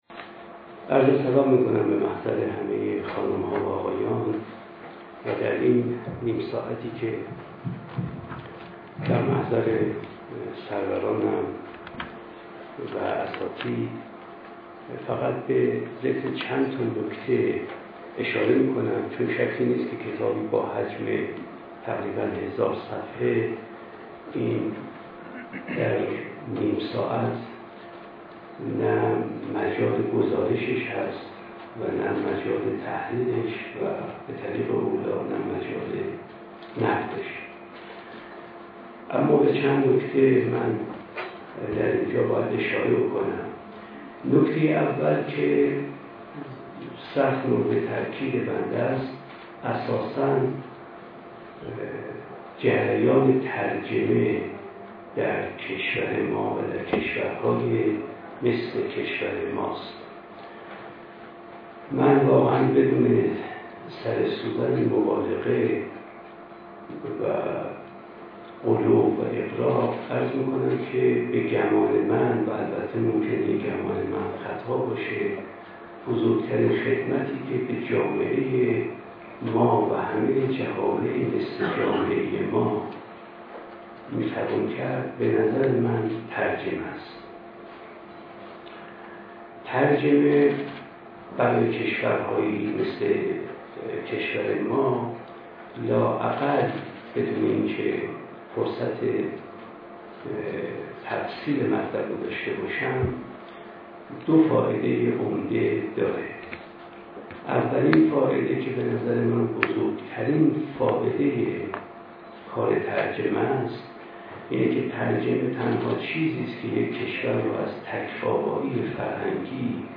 فرهنگ امروز: فایل حاضر سخنرانی مصطفی ملکیان در نقد و بررسی «دانشنامه فلسفه اخلاق» است که در کتابخانه ملی در تاریخ ۱۰/۰۳/۱۳۹۳ ایراد شده است.